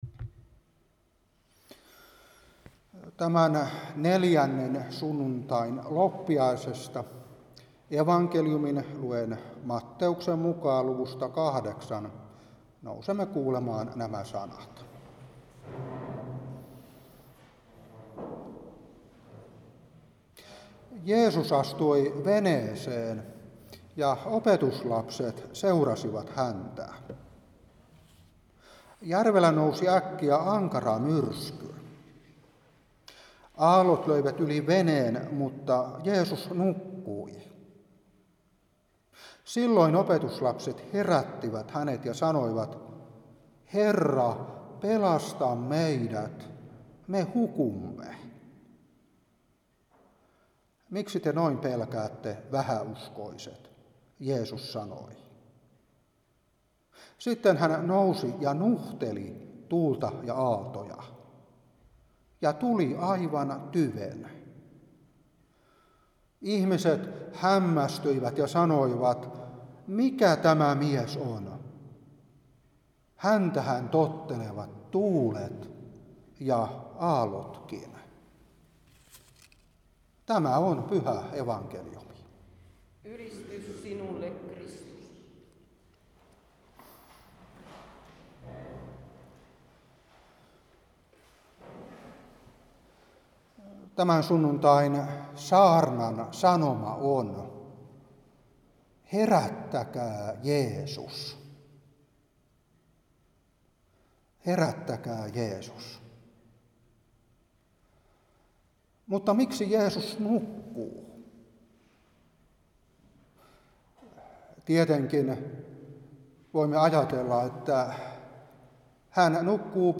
Saarna 2022-1.
Vaasa